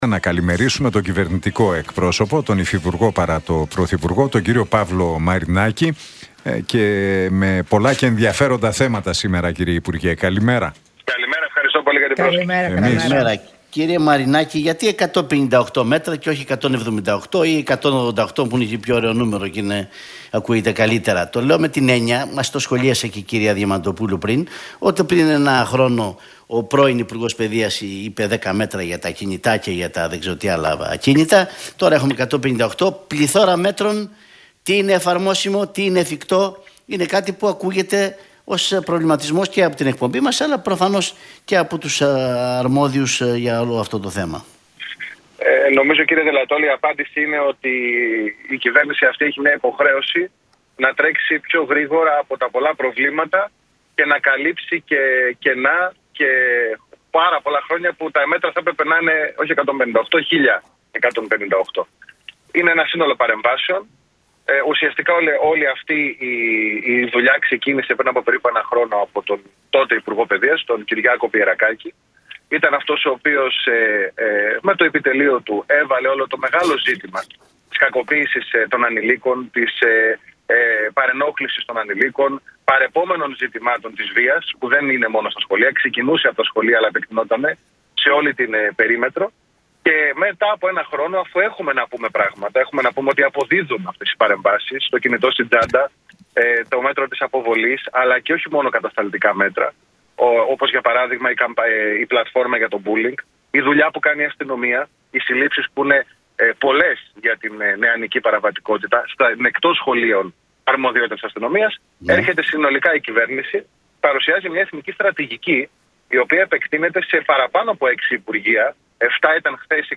υφυπουργός παρά τω Πρωθυπουργώ και κυβερνητικός εκπρόσωπος, Παύλος Μαρινάκης
από την συχνότητα του Realfm 97,8